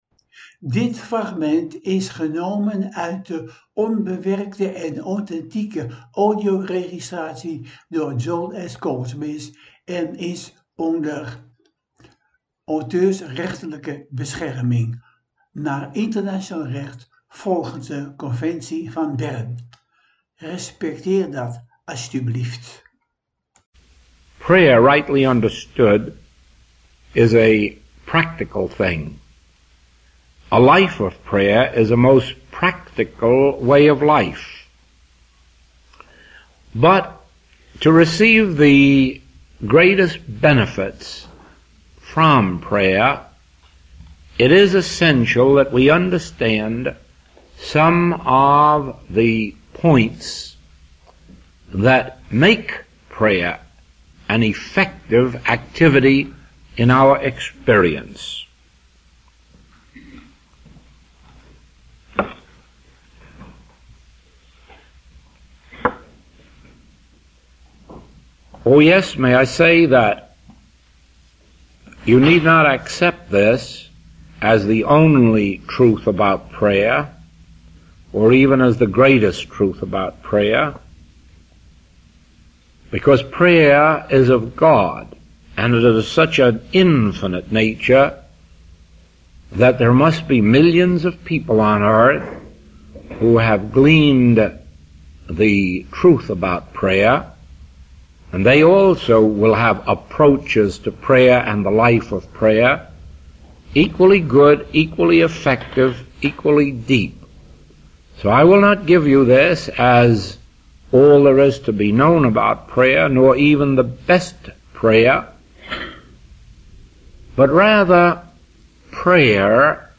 ENKELE FRAGMENTEN UIT DE AUTHENTIEKE, ONBEWERKTE AUDIOREGISTRATIES